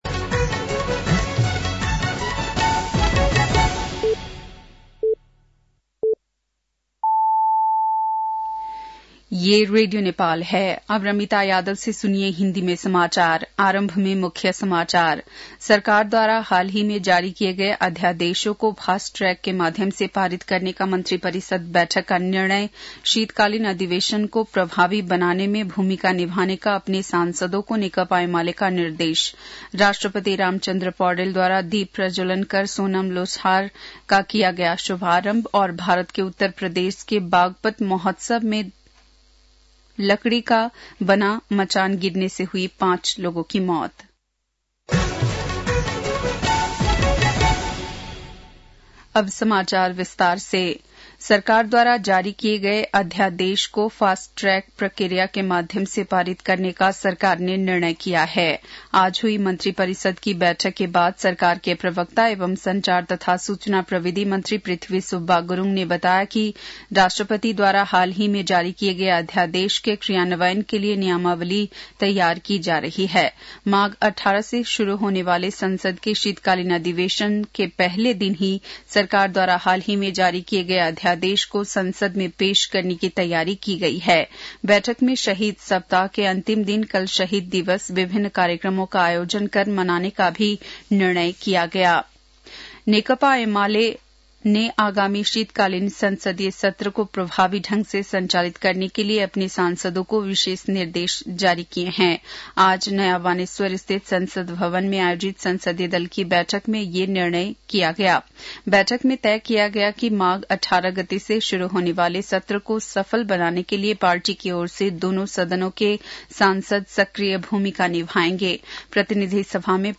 बेलुकी १० बजेको हिन्दी समाचार : १६ माघ , २०८१